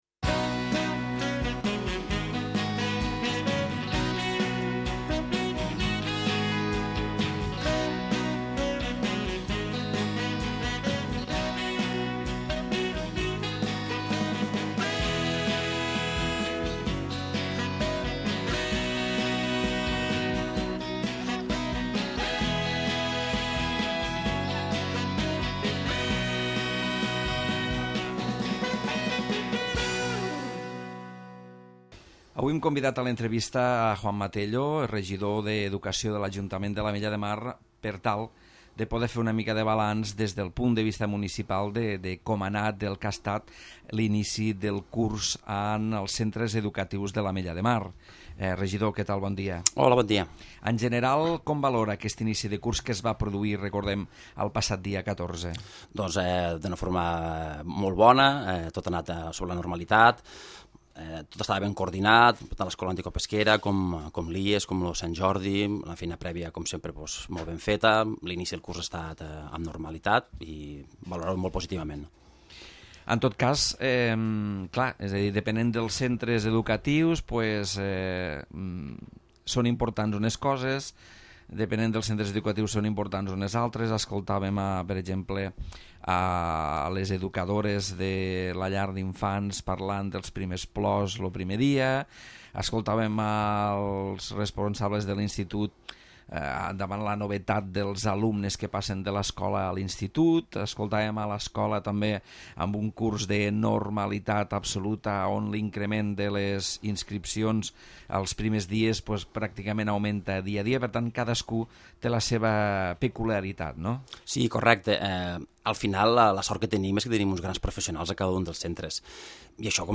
L'entrevista amb Joan Manel Tello